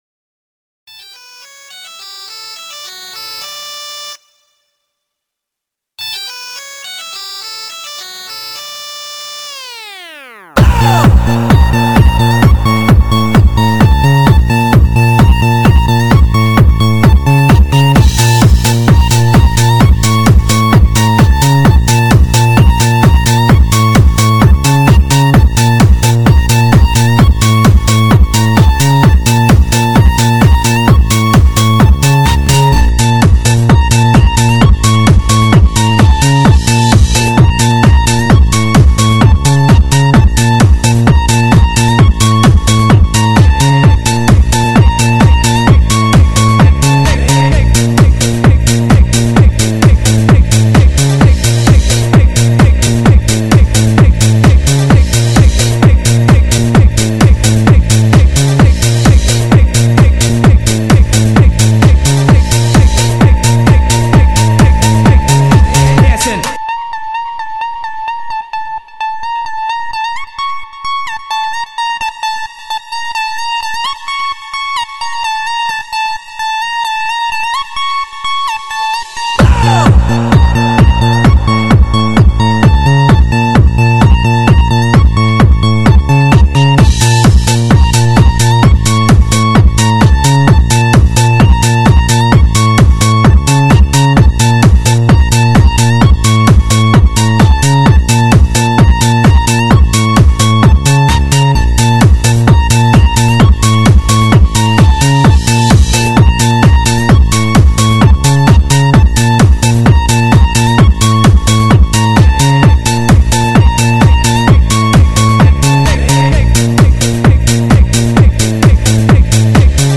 Категория: Клубняк